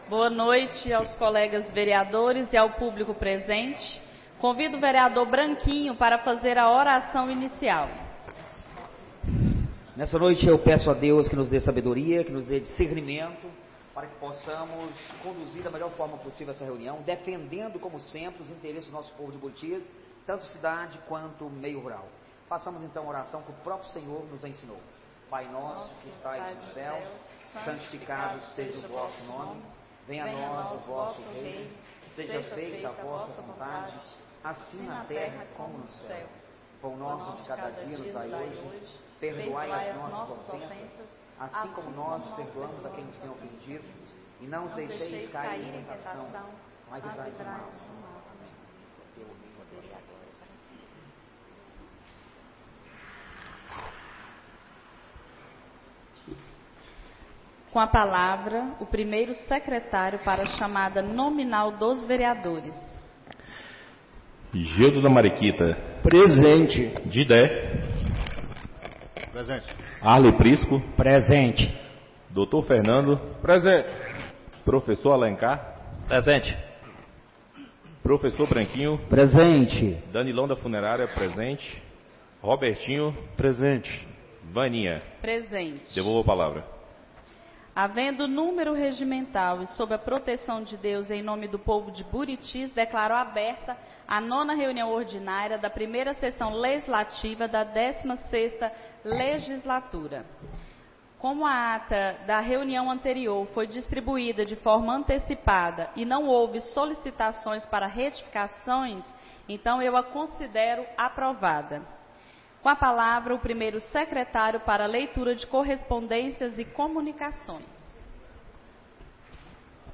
9ª Reunião Ordinária da 1ª Sessão Legislativa da 16ª Legislatura - 17-03-25